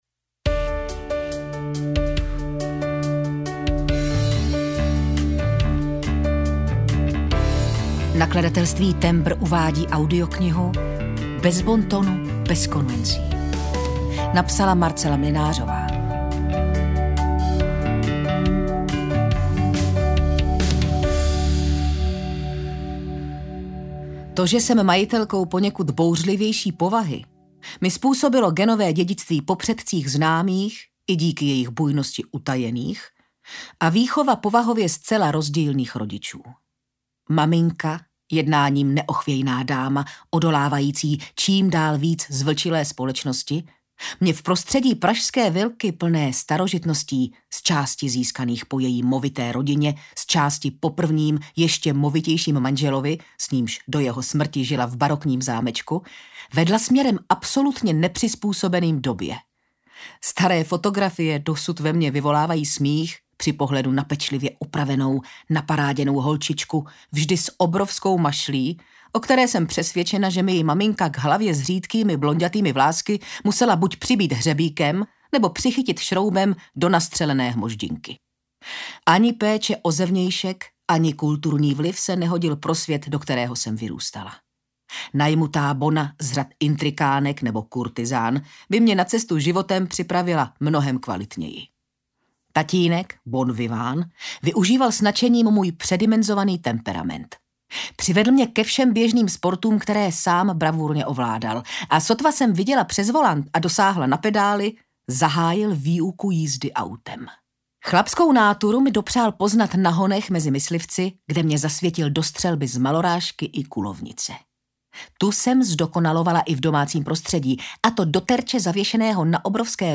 Bez bontonu, bez konvencí audiokniha
Ukázka z knihy